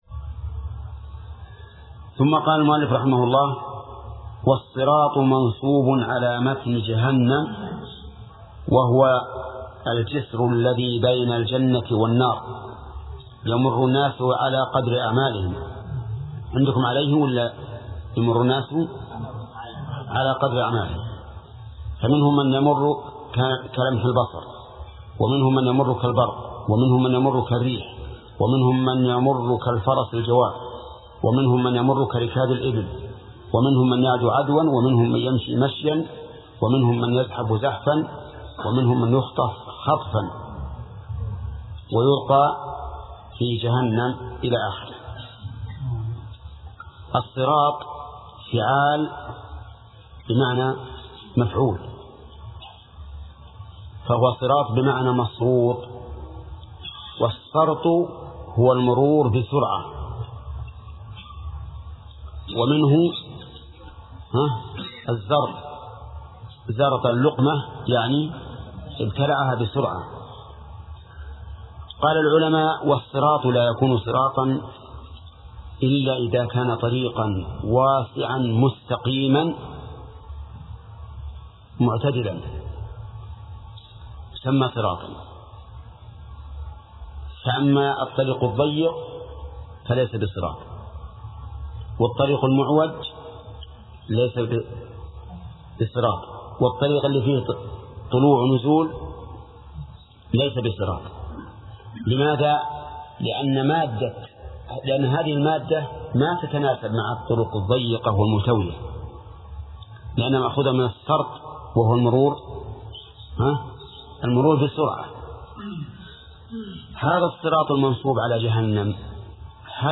درس : 30 : من صفحة: (160)، (قوله: الأمر التاسع مما يكون يوم القيامة ....)، إلى صفحة: (180)، (قوله: وأصناف ما تضمنته الدار الآخرة ...).